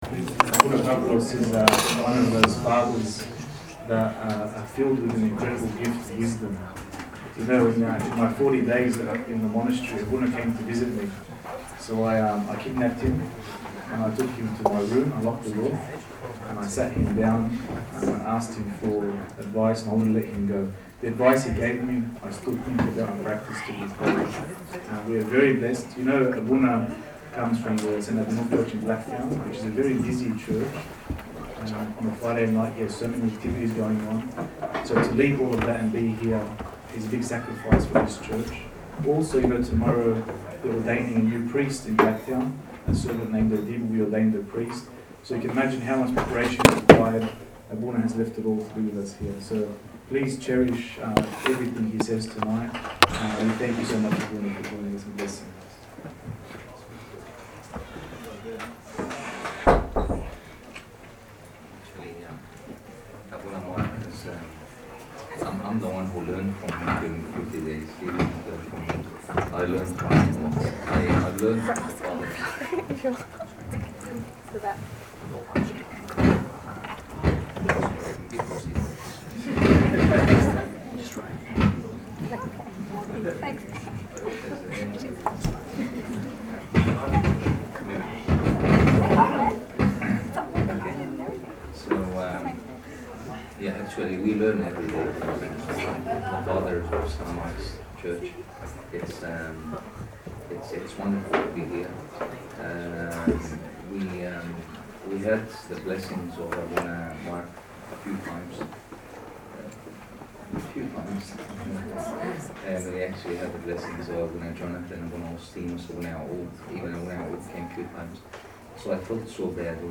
Youth Meeting Talks